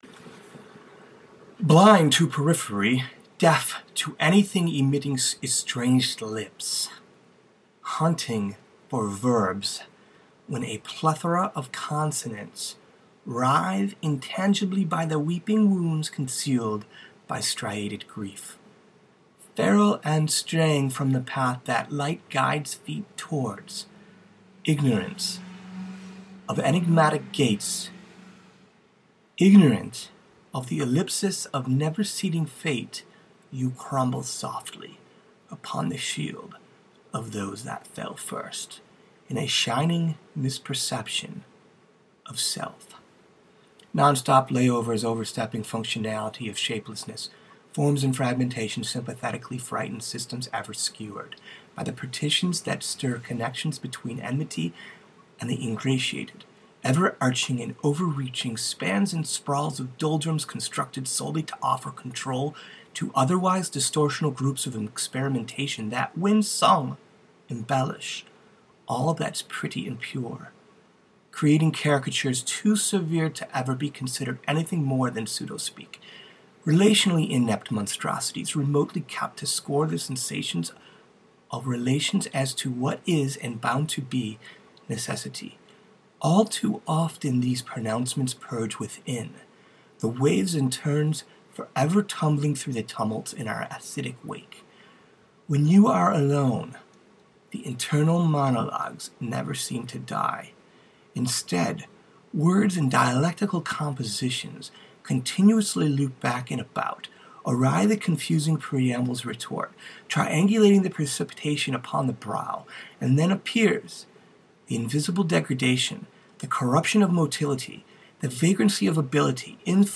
Recording, Reading, Poetry, Poem, Prose poetry,